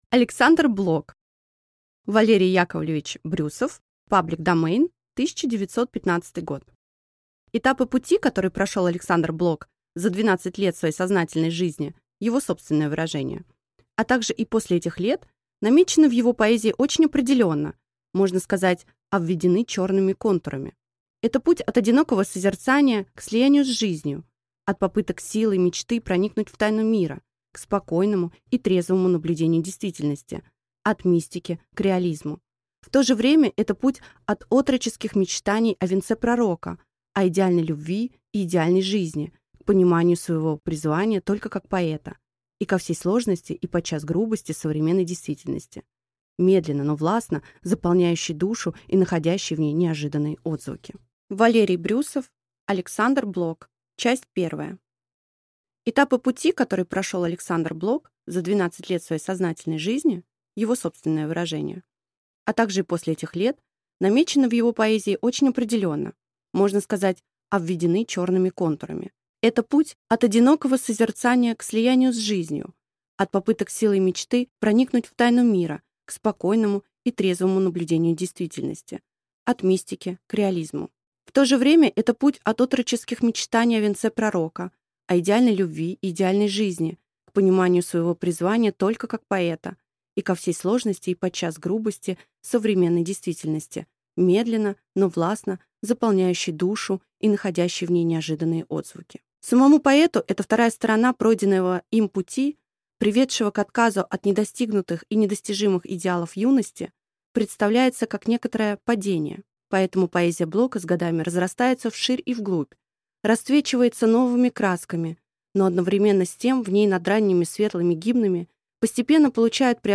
Аудиокнига Александр Блок | Библиотека аудиокниг